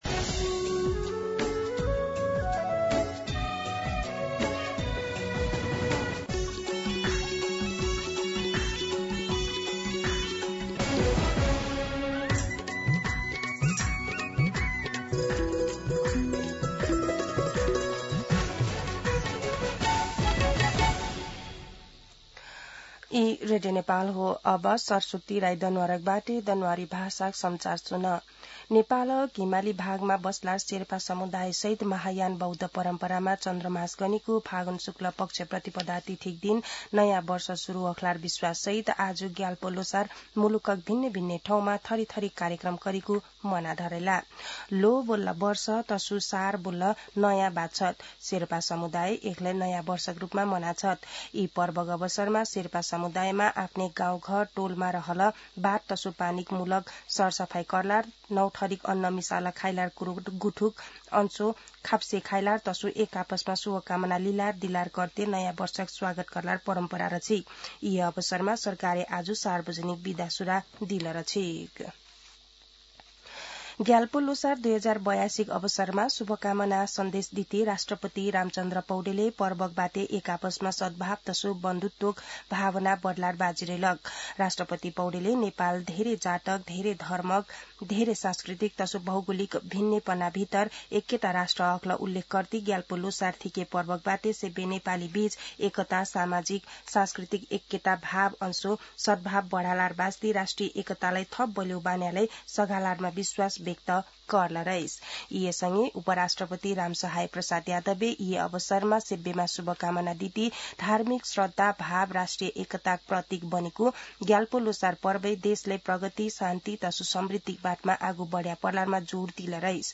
दनुवार भाषामा समाचार : ६ फागुन , २०८२
Danuwar-News-06.mp3